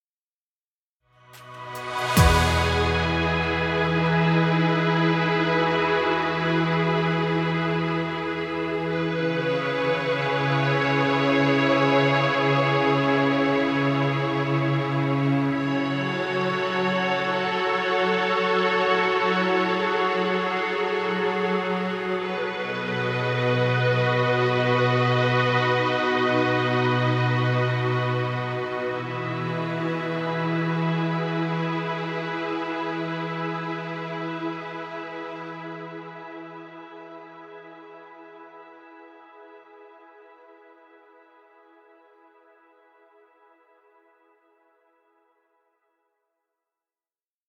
Chillout music..